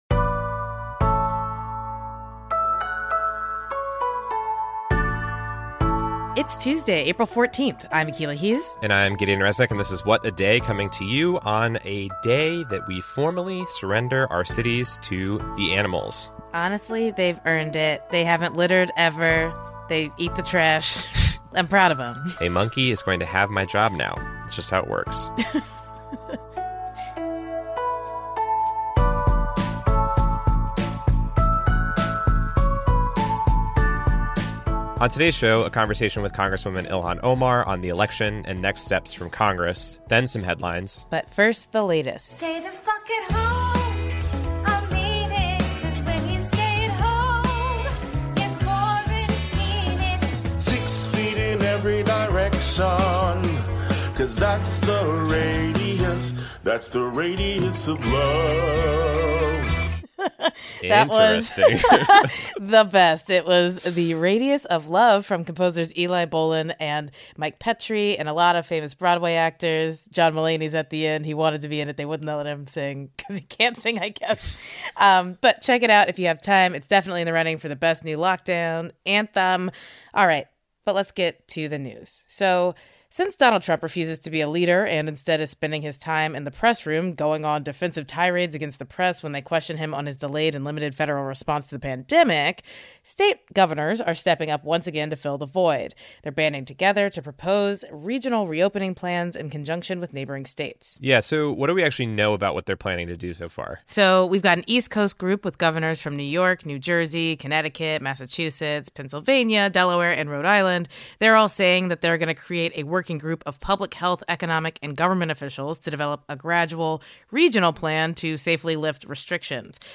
We interview Congresswoman Ilhan Omar about what progressives want from presumptive Democratic presidential nominee Joe Biden and what steps Congress should take next to address the pandemic.